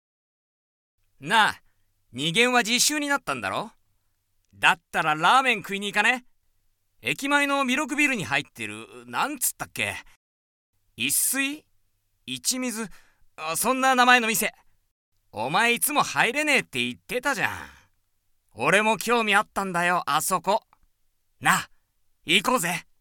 ボイスサンプル
台詞